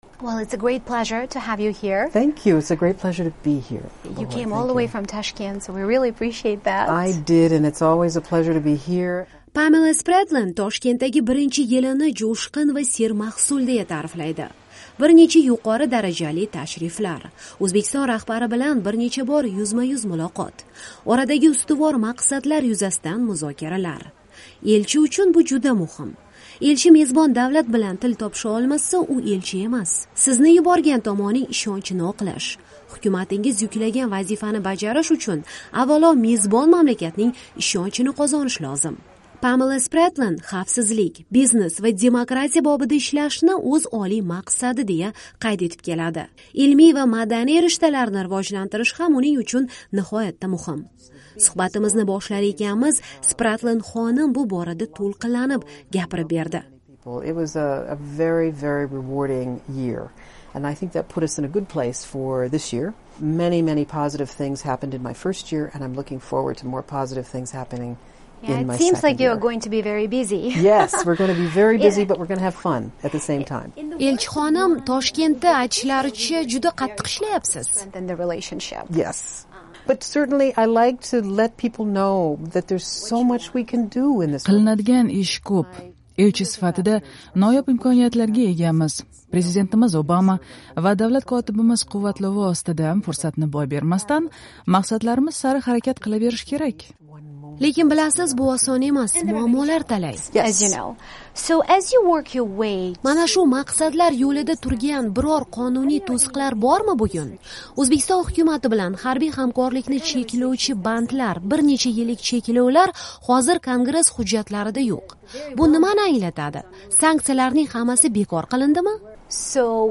AQSh-O'zbekiston: Elchi Pamela Spratlen bilan intervyu